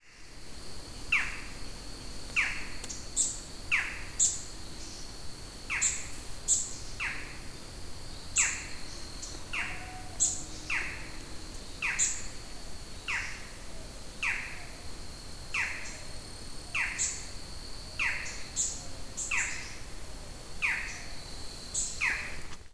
Halcón Montés Chico (Micrastur ruficollis)
Nombre en inglés: Barred Forest Falcon
Fase de la vida: Adulto
Localidad o área protegida: Alto Vera
Condición: Silvestre
Certeza: Observada, Vocalización Grabada
Micrastur-ruficollis.mp3